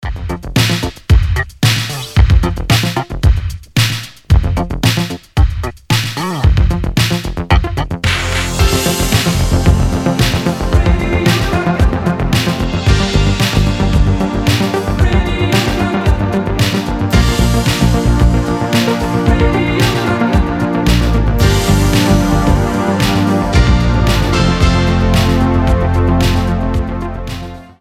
• Качество: 320, Stereo
гитара
ритмичные
Synth Pop
спокойные
80-е
Интересный проигрыш из хитовой песни в стиле арена-рок